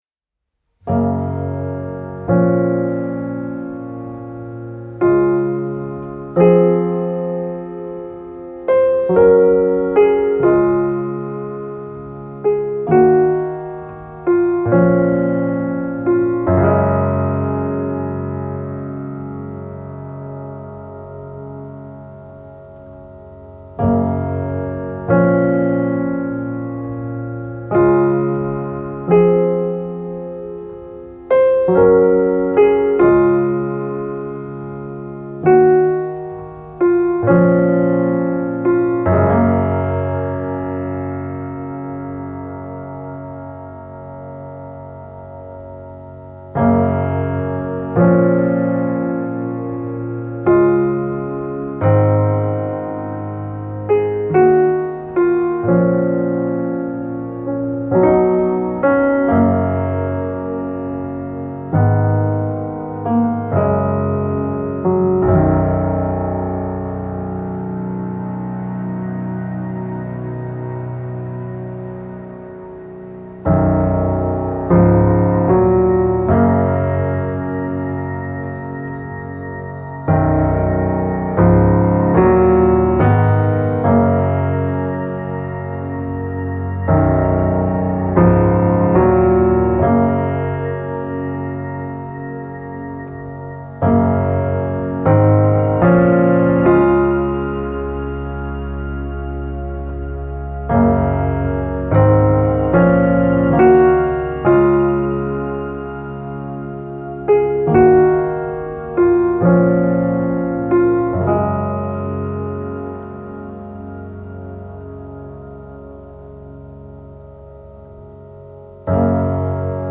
Saudade A soft, reflective solo piano piece I wrote while thinking on childhood memories.